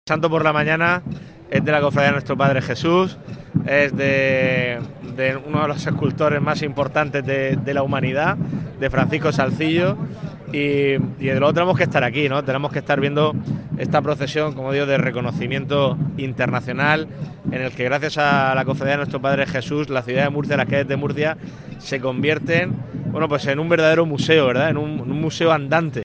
Contenidos Asociados: Declaraciones del presidente de la Comunidad, Fernando López Miras, sobre la procesión de los Salzillos de la Real y Muy Ilustre Cofradía de Nuestro Padre Jesús Nazareno (Documento [.mp3] 295,84 KB) Destacados Conciliación laboral (SMAC) e-Tributos Pago a Acreedores Participación ciudadana Canal Mar Menor © Todos los derechos reservados.